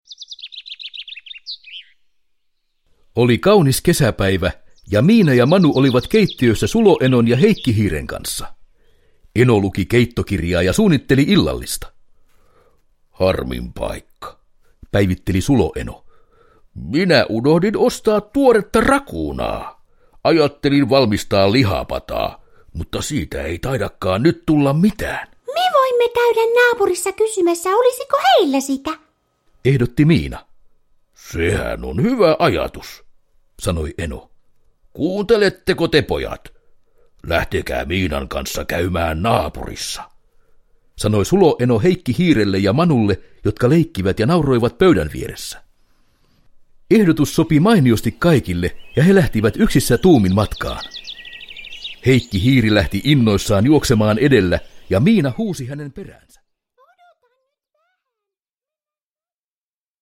Miina ja Manu puutarhassa – Ljudbok – Laddas ner